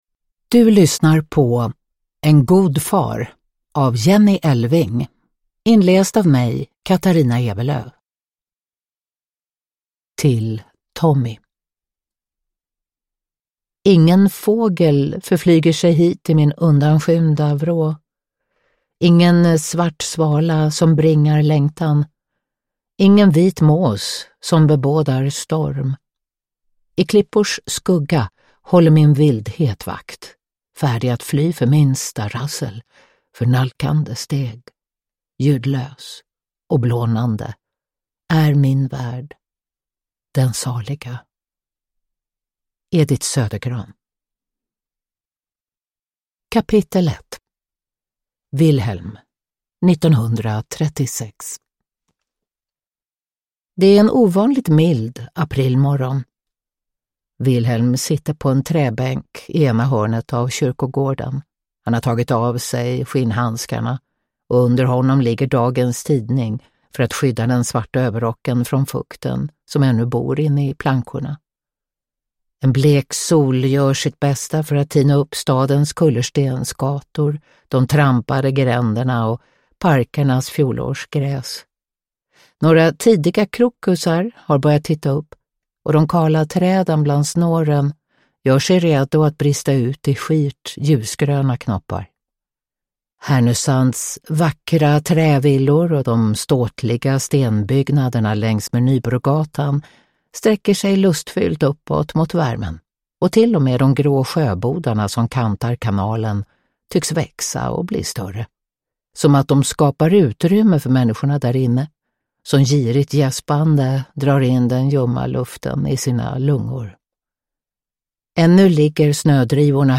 Uppläsare: Katarina Ewerlöf
Ljudbok